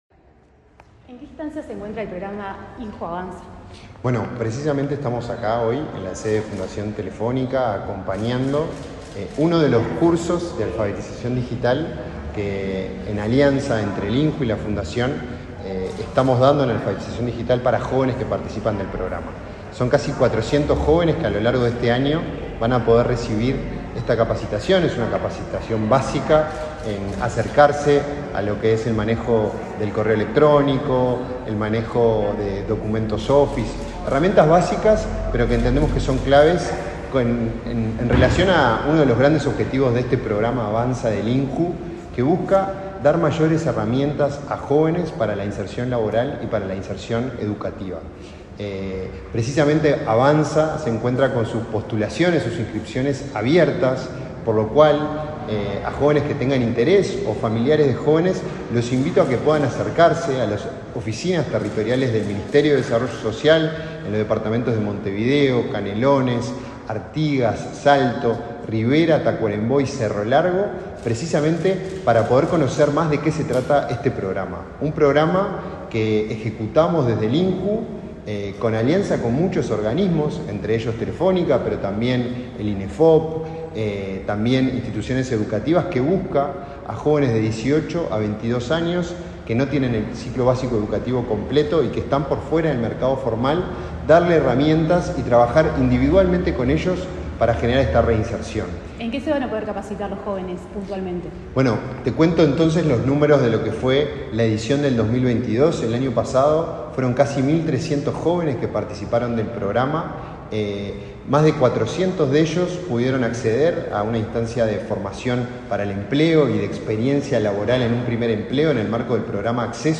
Entrevista al director del INJU, Felipe Paullier